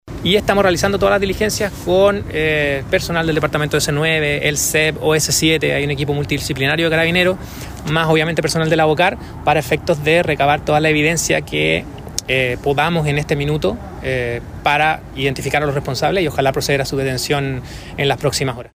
Al respecto, el fiscal ECOH Felipe Olivari, informó de las diligencias policiales que se llevan adelante: